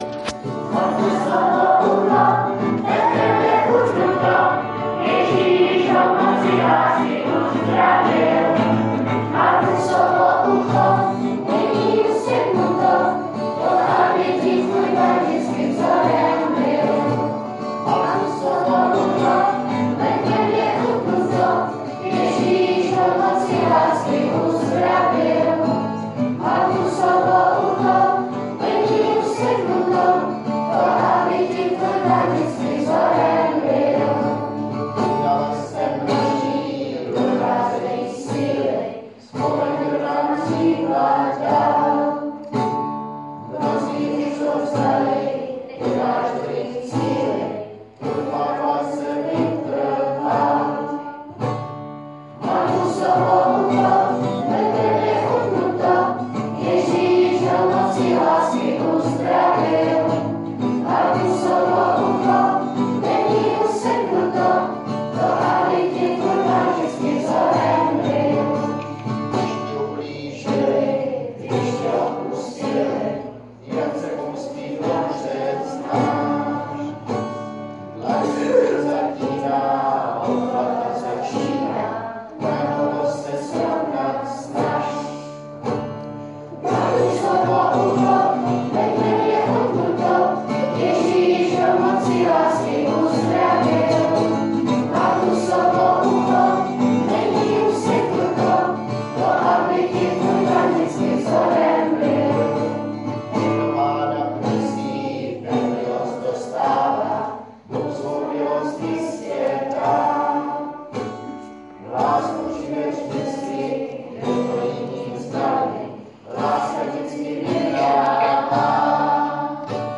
píseň